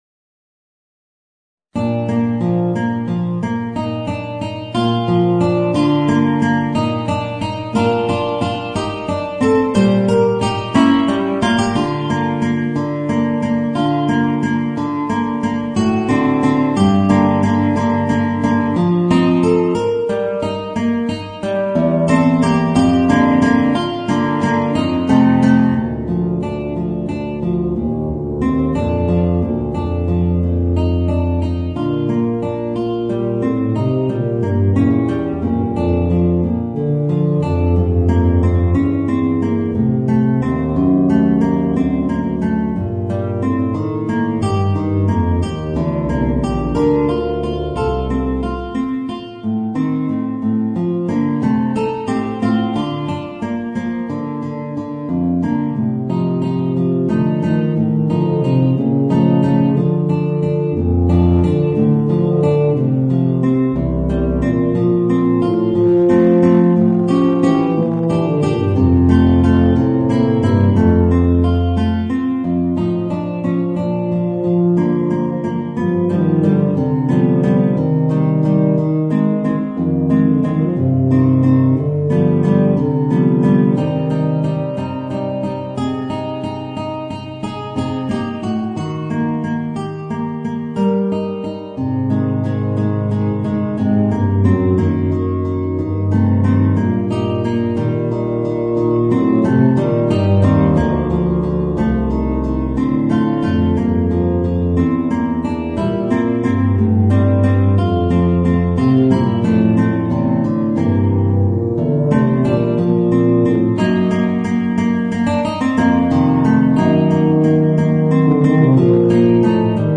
Voicing: Bb Bass and Guitar